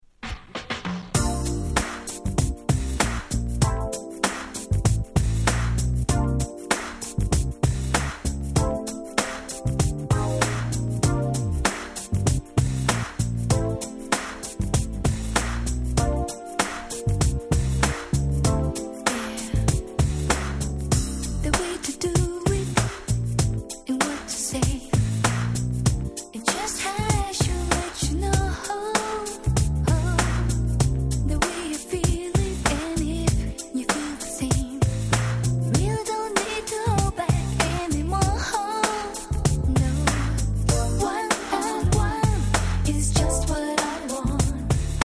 Nice 90's R&B !!
これがまためちゃめちゃ良いR&Bに仕上がっております！！！！！